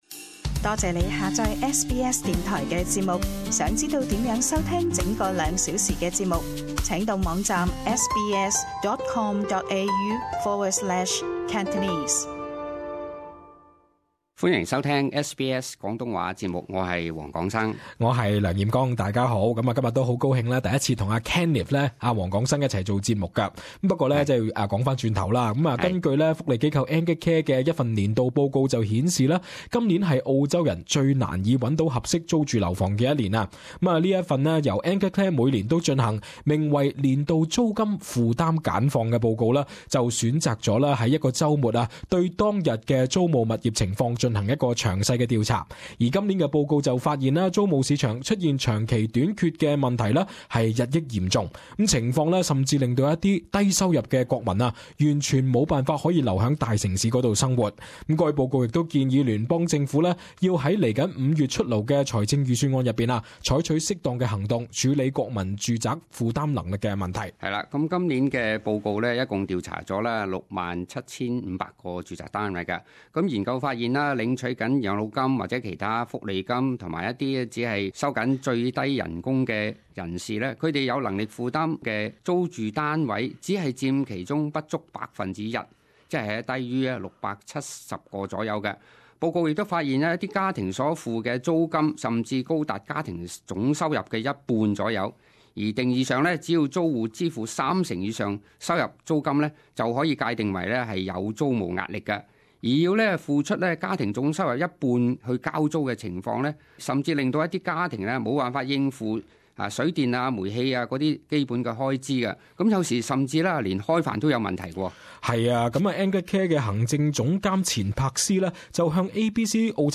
時事報導